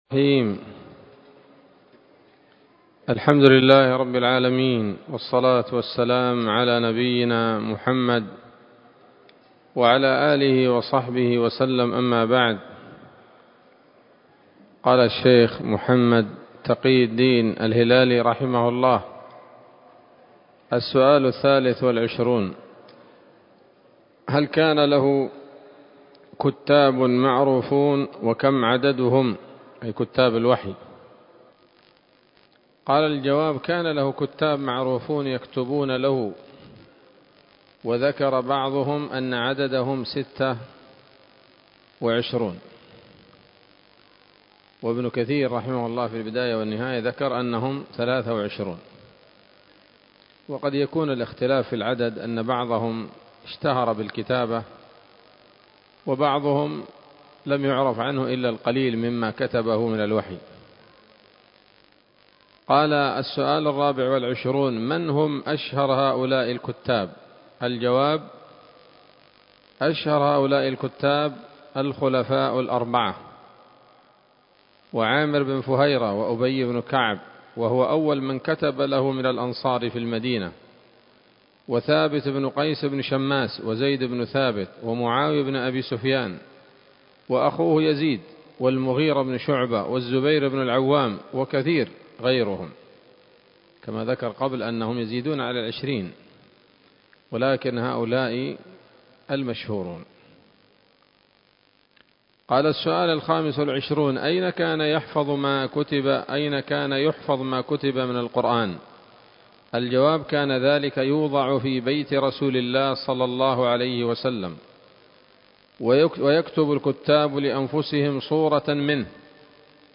الدرس الثامن من كتاب نبذة من علوم القرآن لـ محمد تقي الدين الهلالي رحمه الله